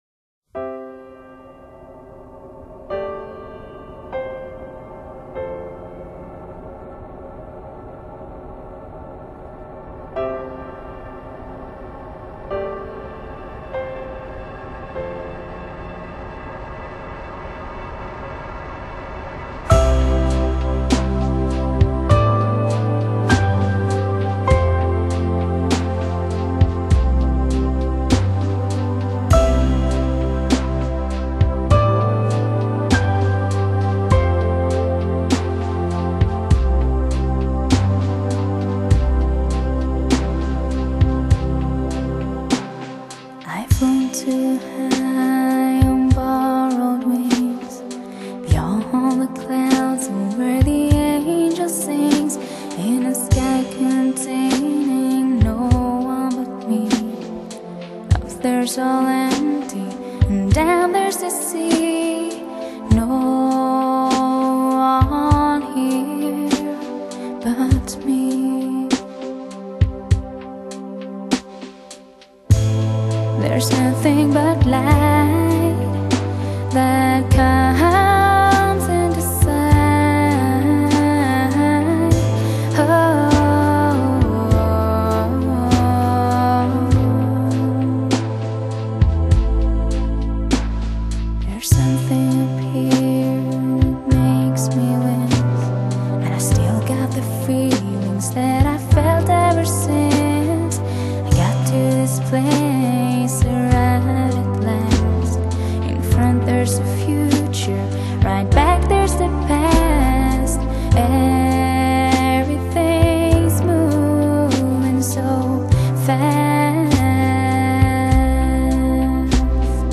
Genre: Pop | RAR 3% Rec.